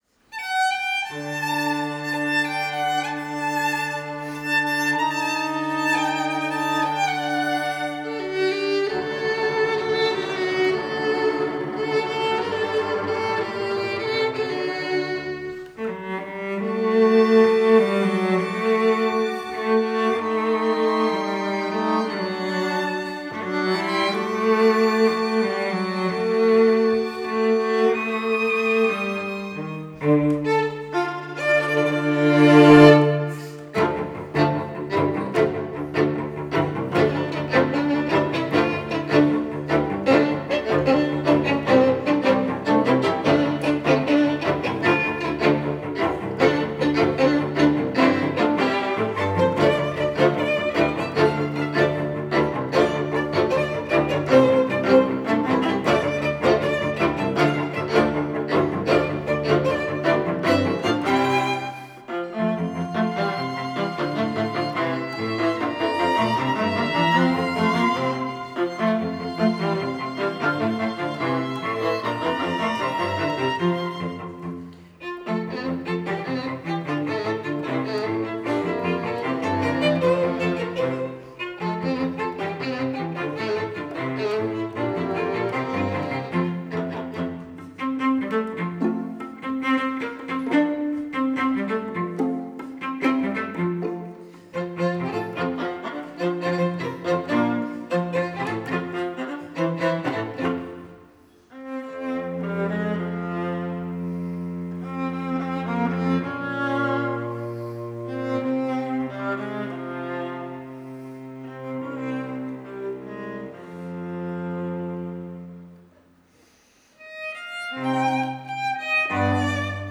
Avec le quatuor Akhtamar 2022
Interprété par Akhtamar quartet. Projet mis en place par Musiq3 & la Commune d’Ottignies Louvain-la-Neuve Ferme du Biéreau – 7 juin 2022